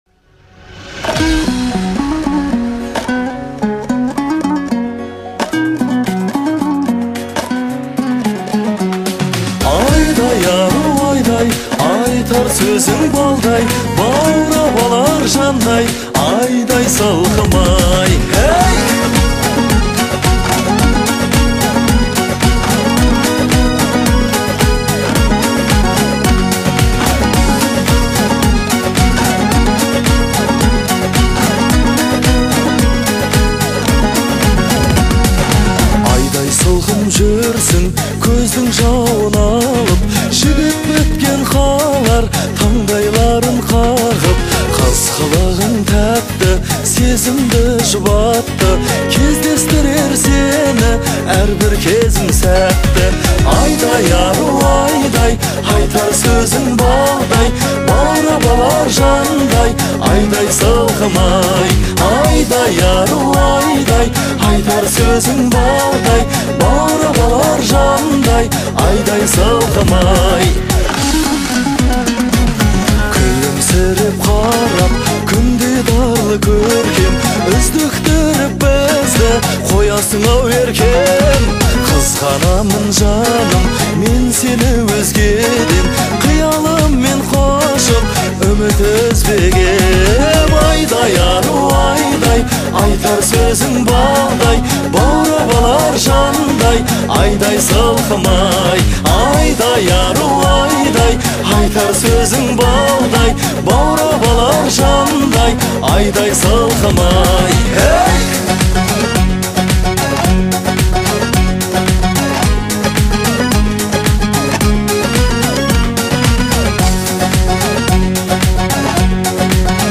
это проникновенная песня в жанре казахской поп-музыки